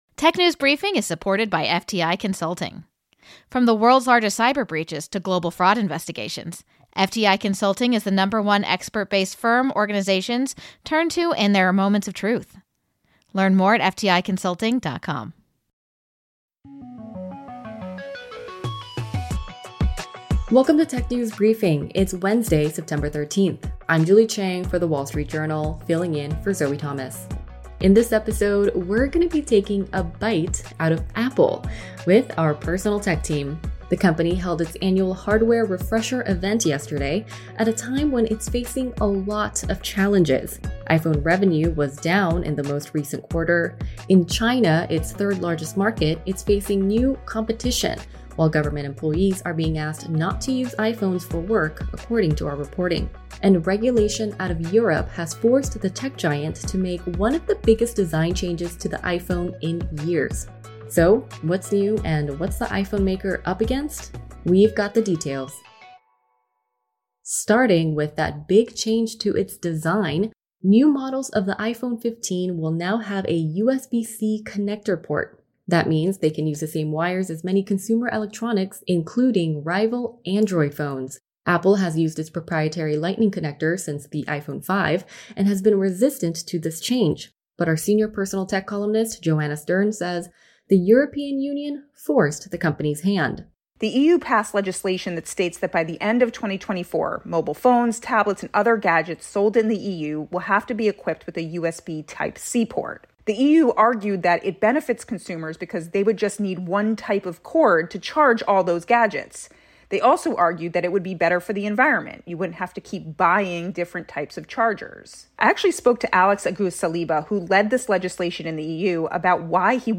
Apple’s latest product updates offer a glimpse at some of the challenges the iPhone maker is facing. In this all Apple episode, members from WSJ’s personal tech team will unpack the new features.